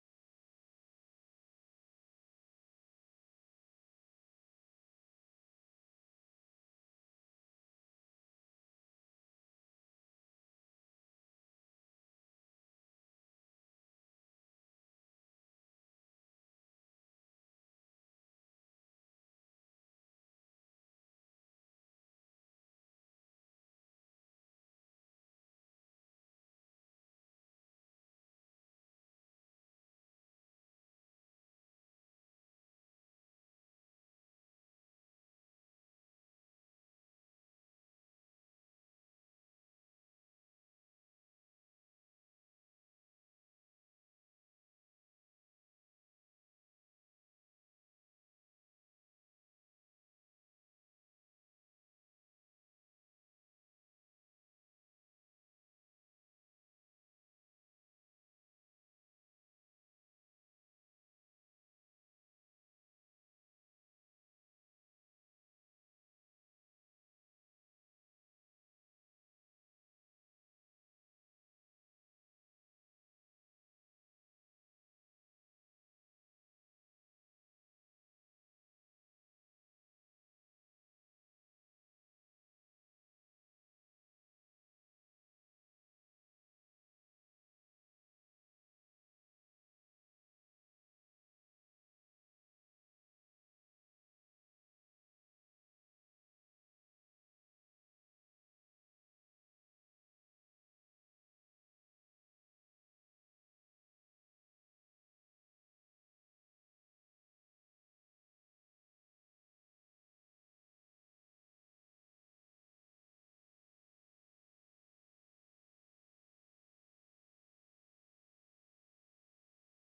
Um programa de saúde visando Moçambique. Meia-hora duas vezes por semana, aos sábados e domingos, produzido em Moçambique por uma equipa de repórteres e especialistas médicos, grupos de jovens e comunidade, bem como grupos culturais.